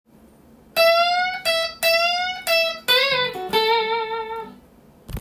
tab譜のkeyはAにしてみました。
Aメジャーペンタトニックスケール